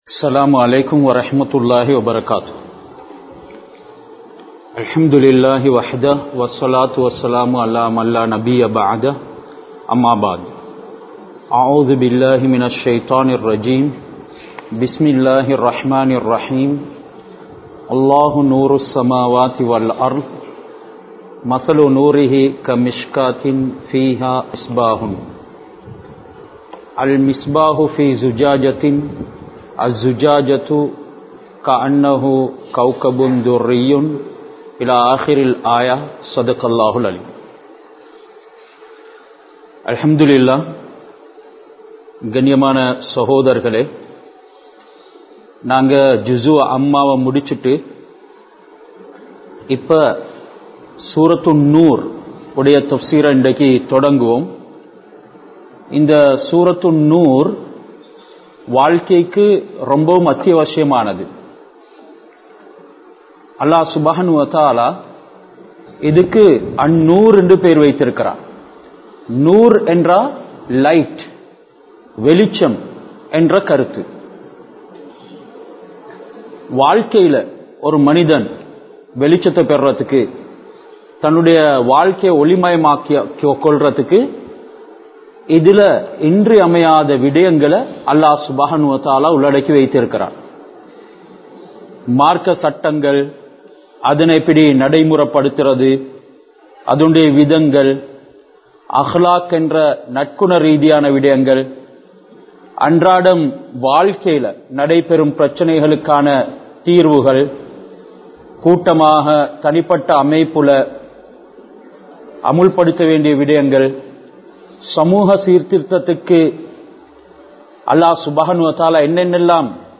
Surah An Noor (Thafseer Verses 1- 2) | Audio Bayans | All Ceylon Muslim Youth Community | Addalaichenai
Hameed Hall Furqaniyyah Arabic College